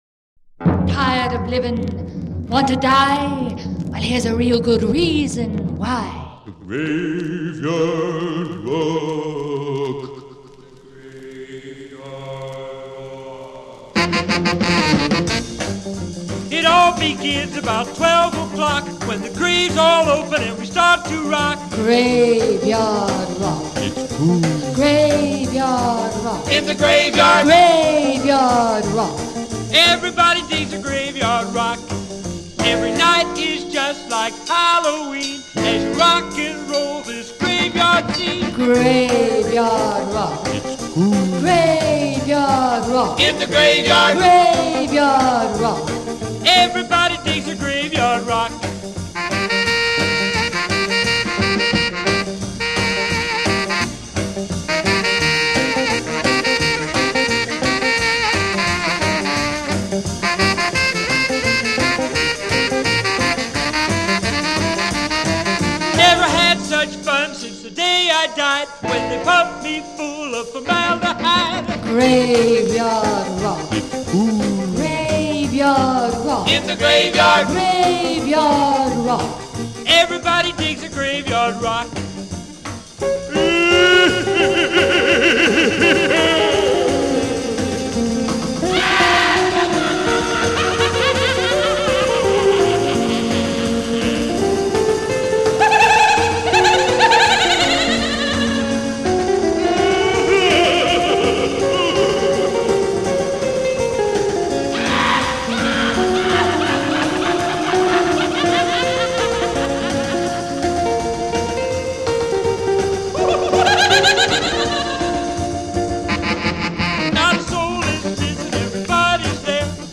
novelty songs